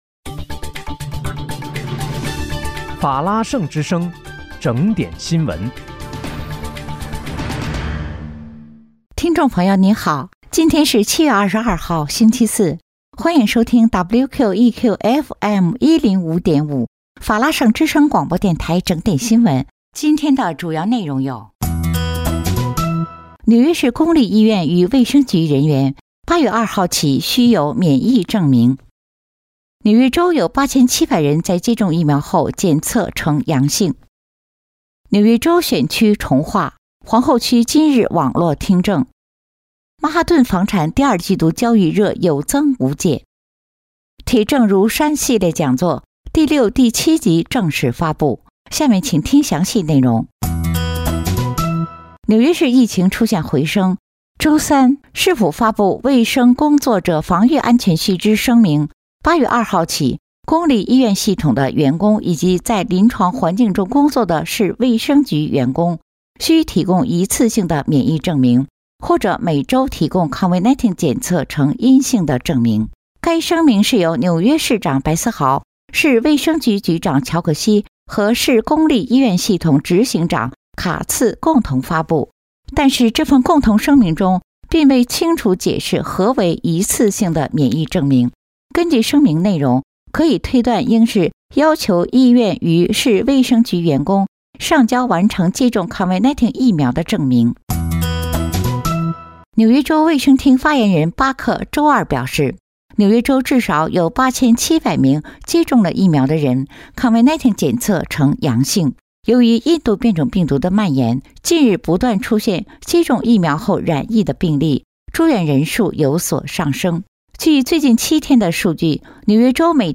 7月22日（星期四）紐約整點新聞